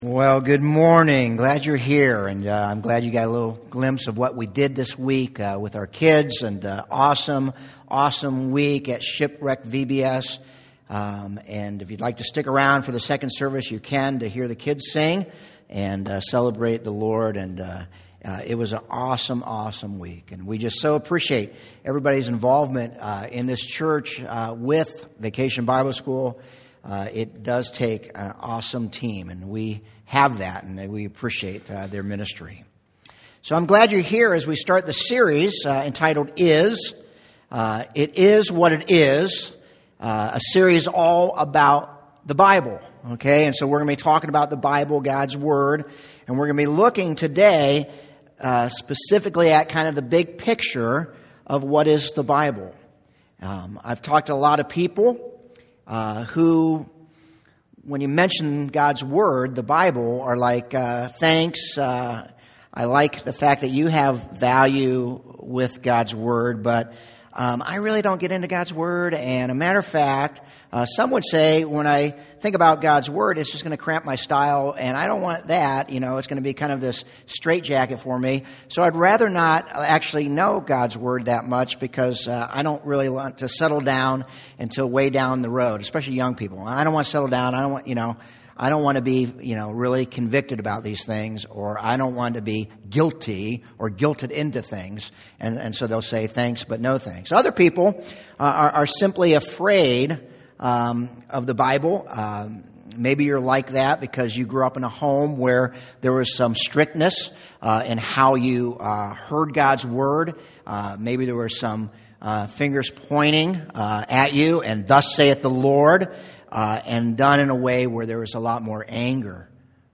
Sun Sermon Recording  Template 7-15-18 a.mp3